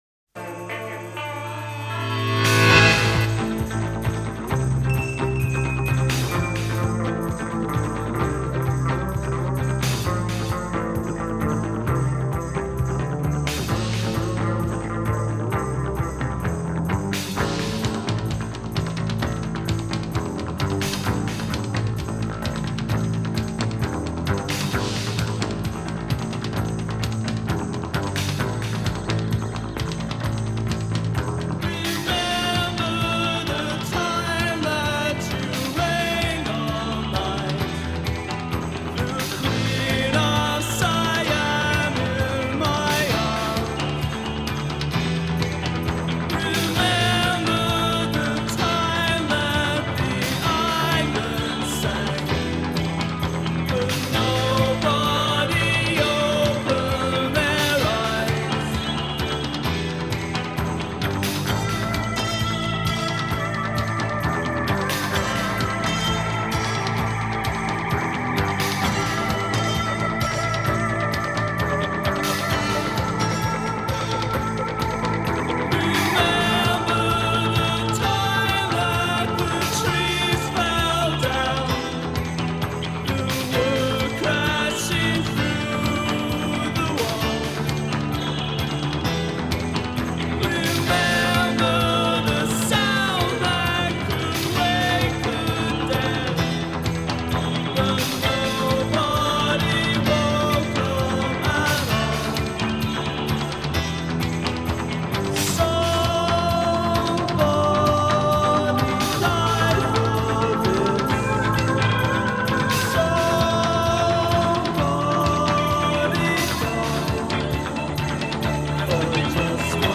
Пост панк Рок